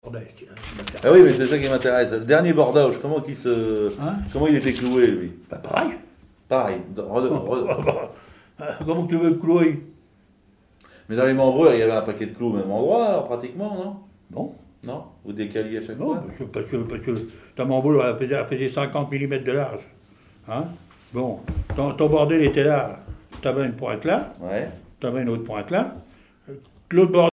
Témoignages d'un charpentier naval sur les techniques locales
Catégorie Témoignage